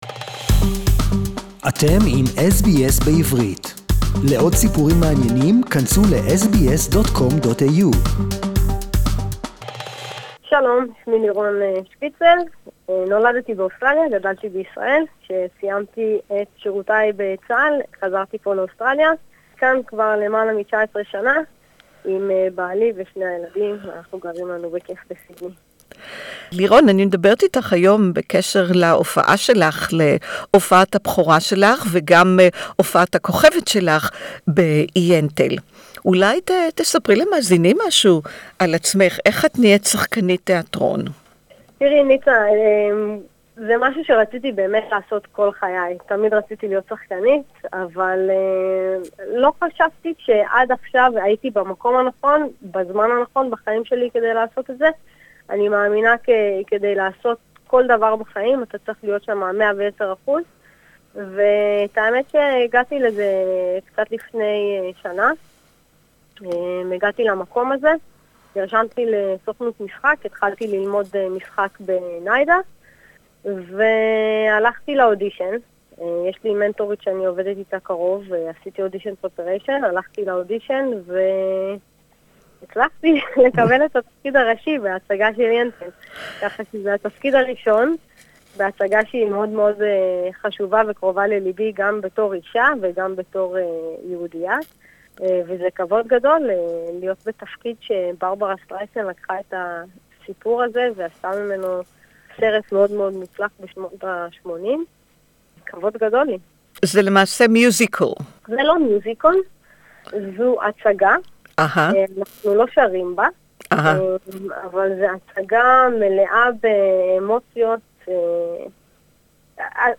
A fascinating story of a19th Century Jewish girl disguises herself as a boy to study the Talmud. The play focuses on the spiritual equality of women in a 19th Century segregated Jewish society that didn’t see women as equals to men (Interview in Hebrew)